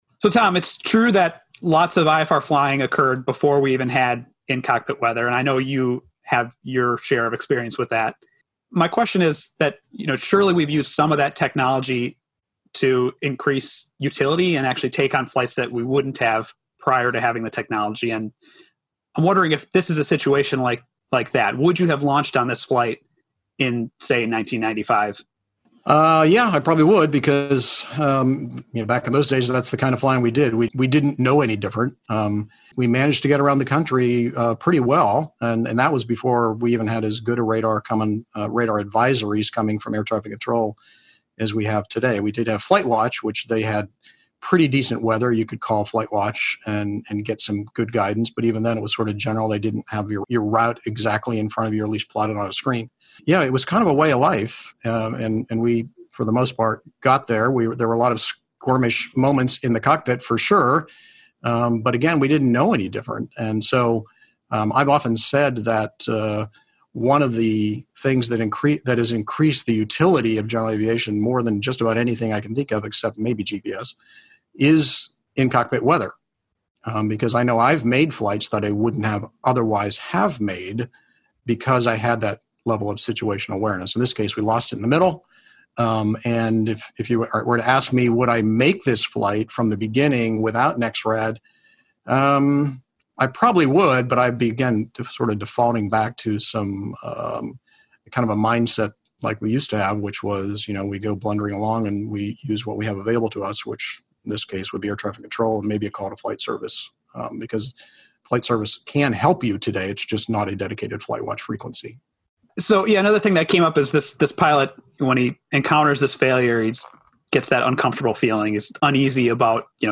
Dataless_over_Dakota_roundtable_revision_Edited.mp3